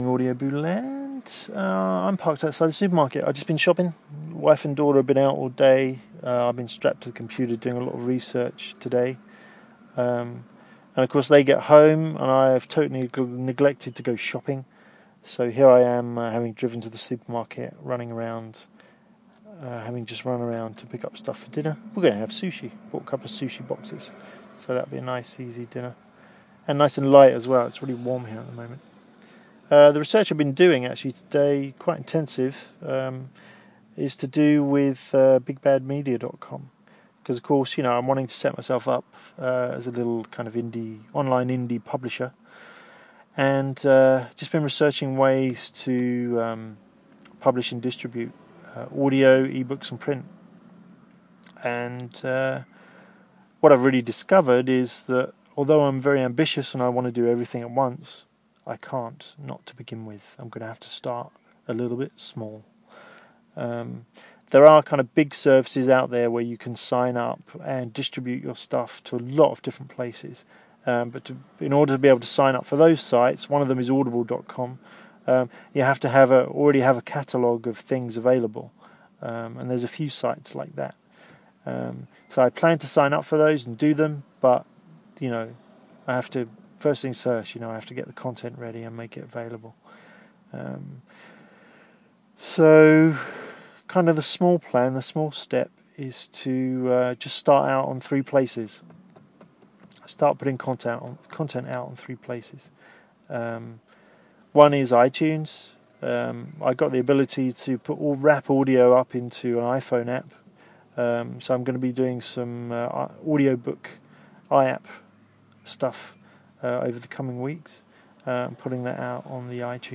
Share Facebook X Next At the end of this I sound like Joey from Friends, which can't be good ...